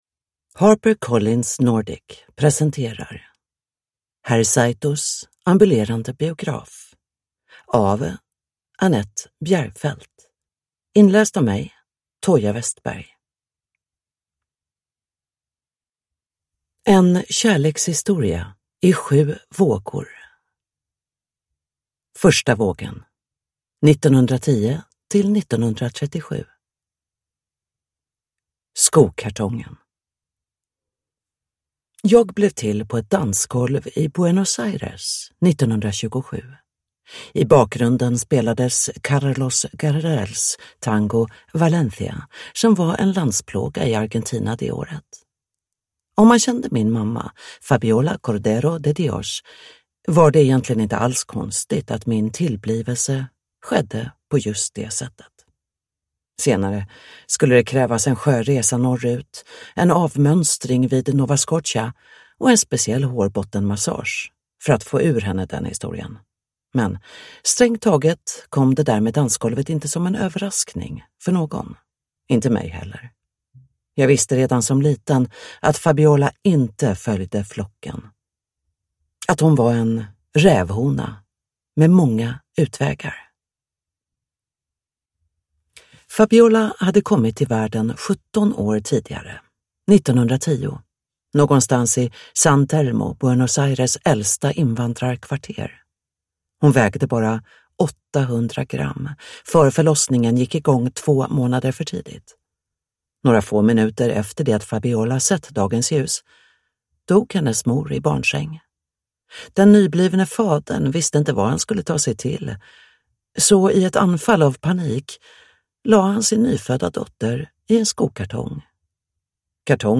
Herr Saitos ambulerande biograf (ljudbok) av Annette Bjergfeldt